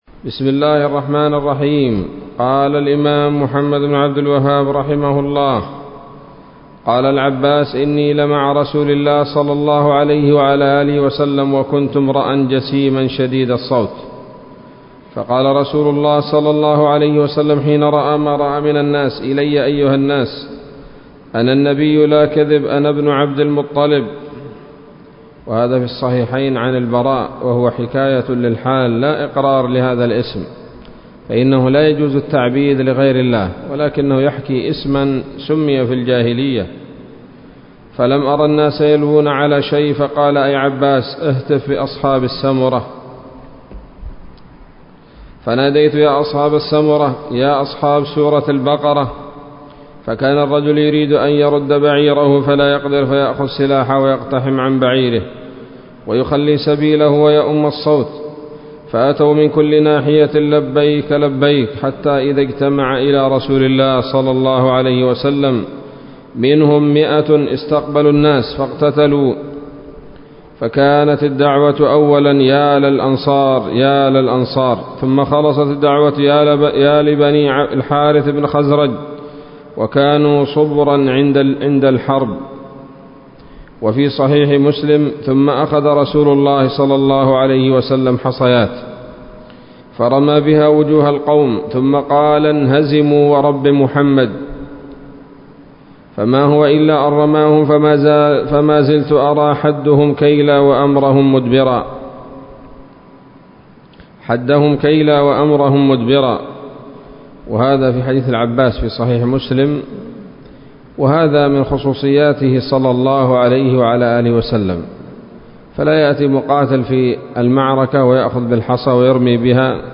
الدرس الثامن والأربعون من مختصر سيرة الرسول ﷺ